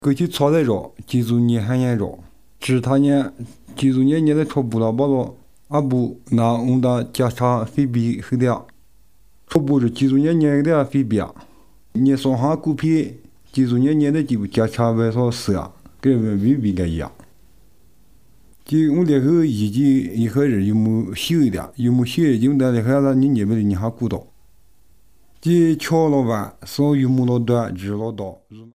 Most use a storytelling approach. These are recorded by mother-tongue speakers
Bible Stories, Discipleship, Music